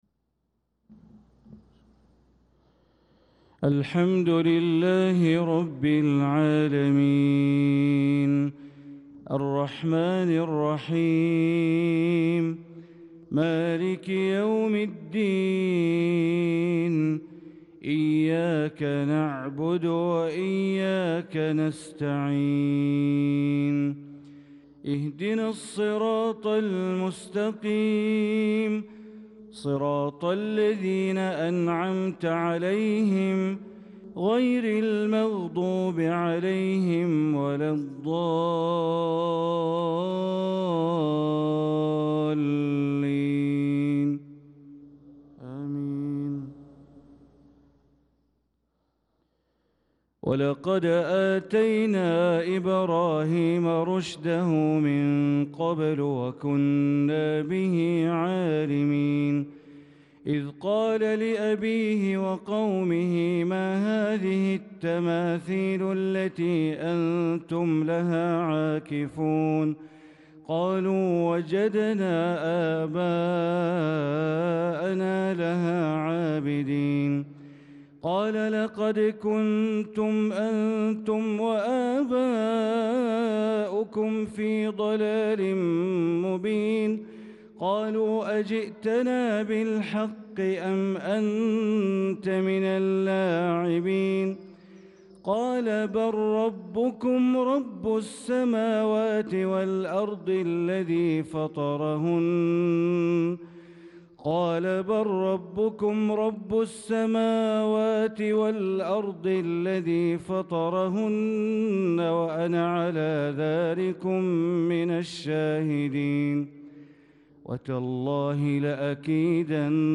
صلاة الفجر للقارئ بندر بليلة 22 ذو القعدة 1445 هـ
تِلَاوَات الْحَرَمَيْن .